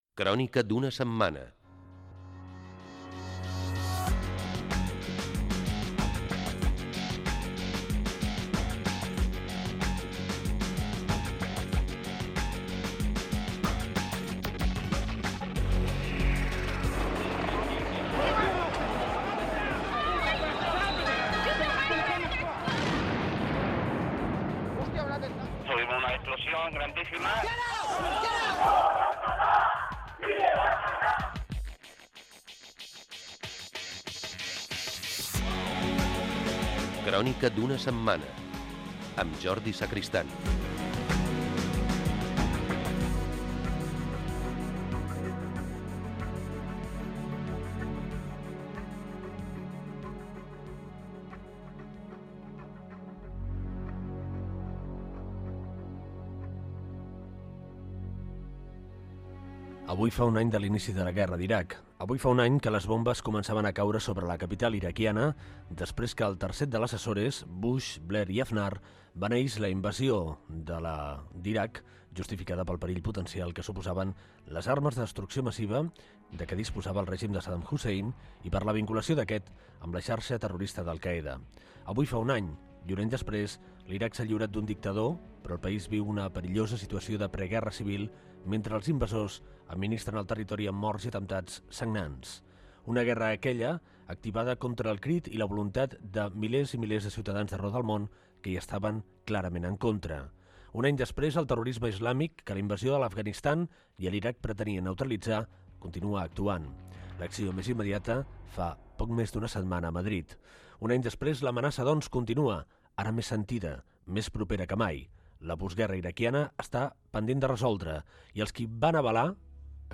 Careta del programa. Un any del començament de la Guerra d'Iraq.
Informatiu